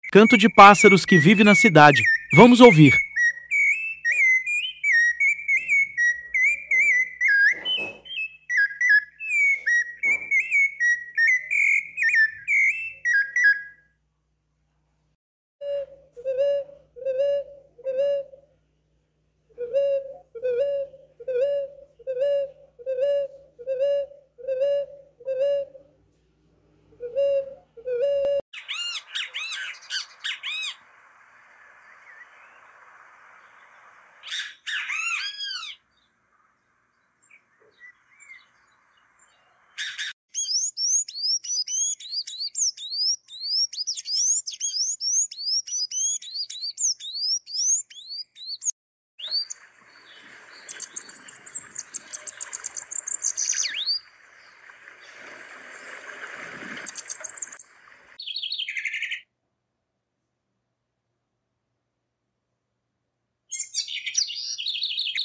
canto de pássaros que vive sound effects free download
canto de pássaros que vive na cidade